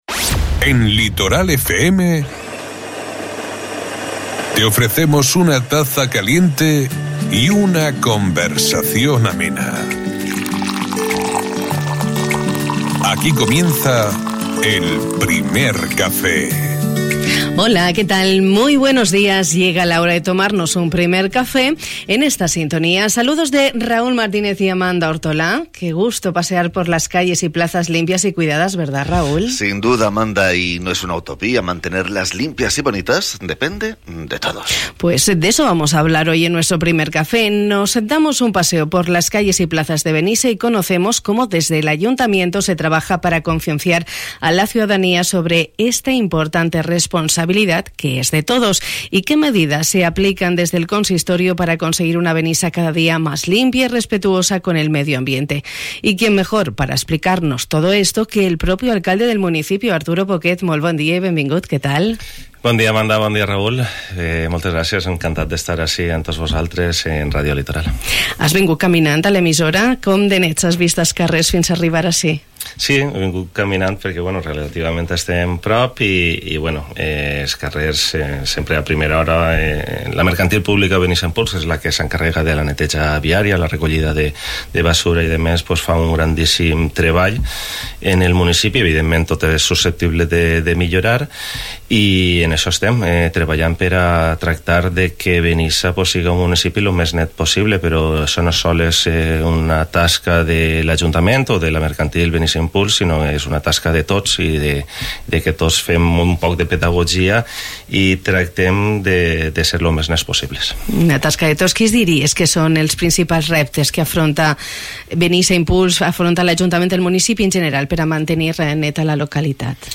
Hemos conocido con el alcalde de Benissa, Arturo Poquet, como se trabaja desde el Ayuntamiento, a través de la empresa pública Benissa Impuls, para conseguir un municipio lo más limpio posible, teniendo en cuenta sus características particulares y la necesaria colaboración de vecinos y visitantes.